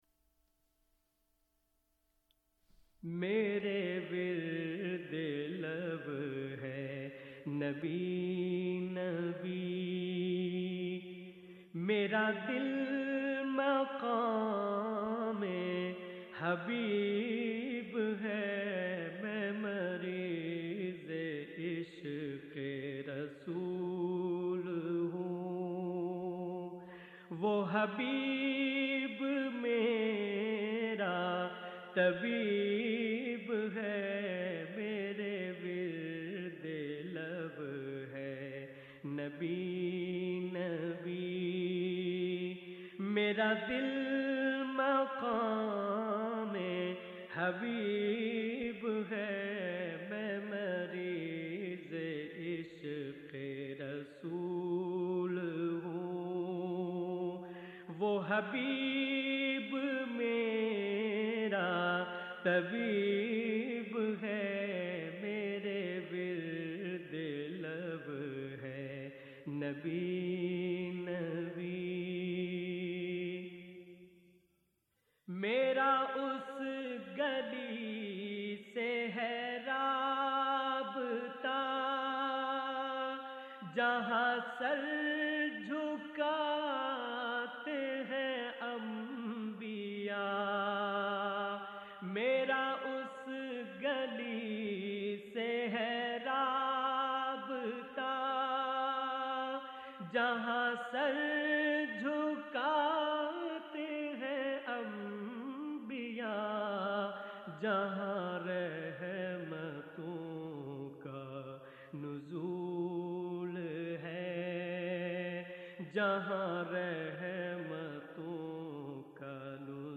نعت رسول مقبول صلّٰی اللہ علیہ وآلہ وسلم